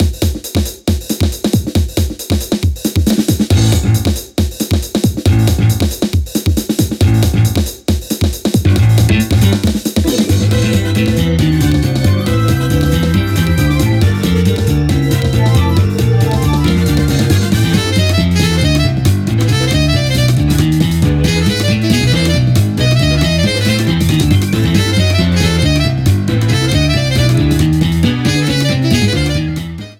Background music